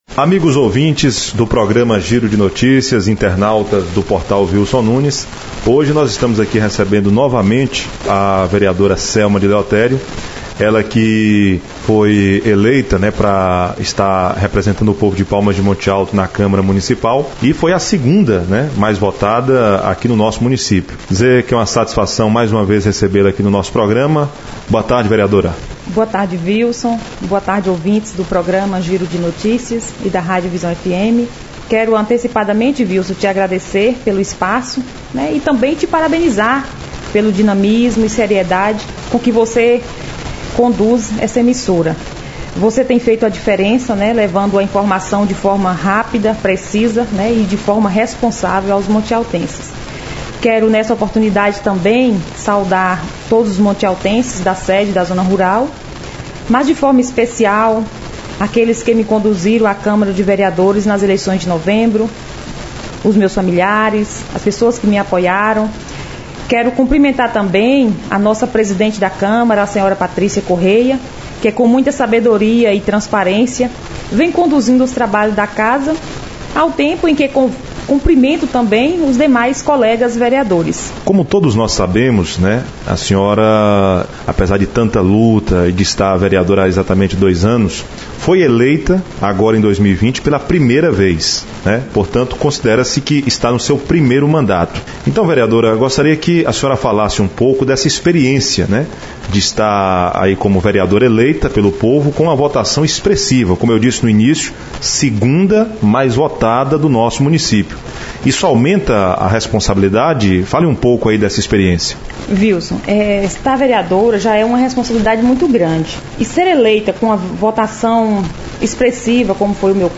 Em entrevista à Rádio Visão FM, vereadora montealtense fala de suas experiências na vereança; ouça - Portal Vilson Nunes
Em entrevista concedida ao Programa de Giro de Notícias/Rádio Visão FM, nesta sexta-feira (7), a vereadora Selma de Leotério, eleita nas eleições de novembro de 2020, fala de suas experiências na vida pública, fazendo um balanço de seus dois anos de vereança.